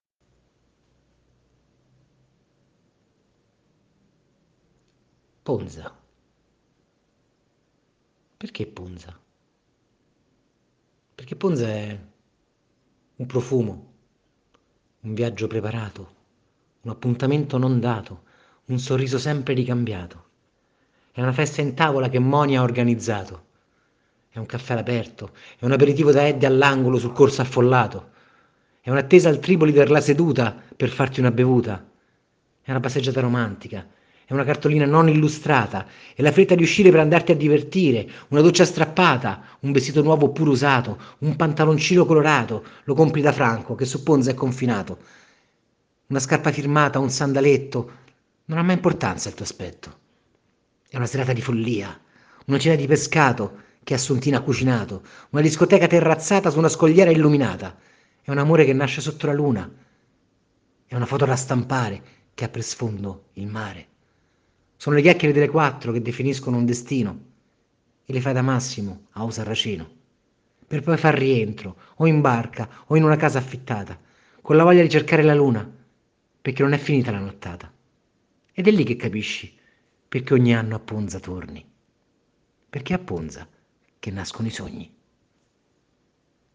una bella poesia rep